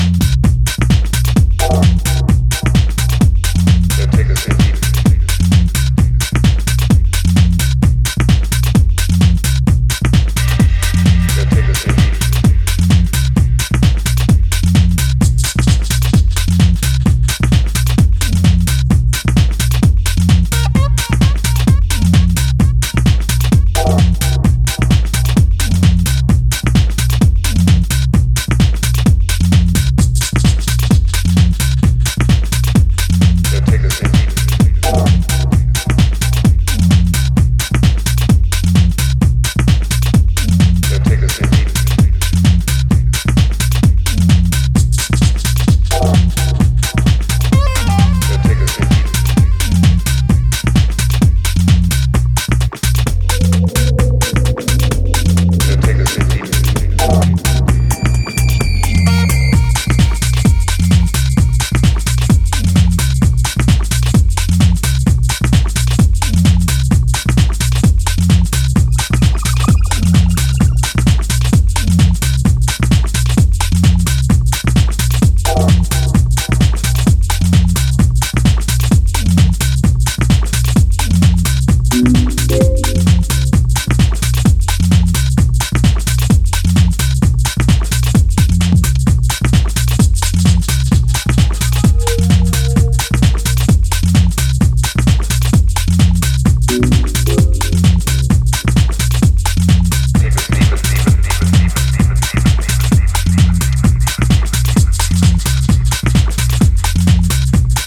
hypnotic corner of the international tech house movement.